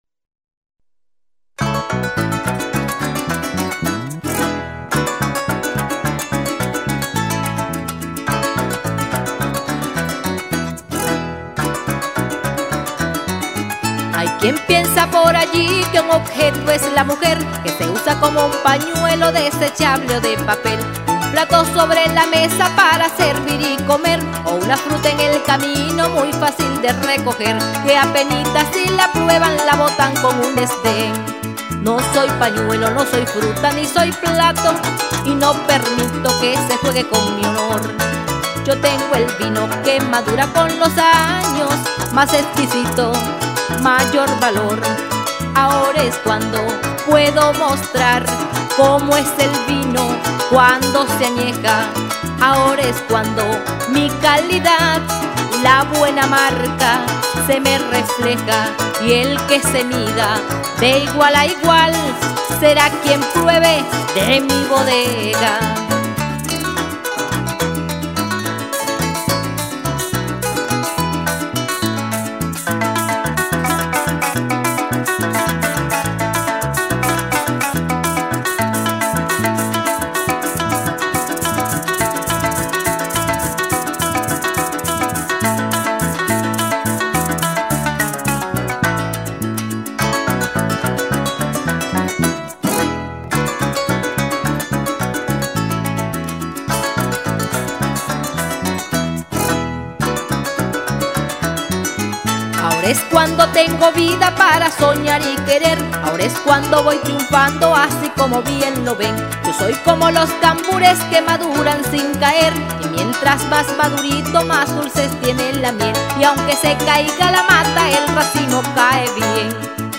Ritmo: Pasaje – Golpe.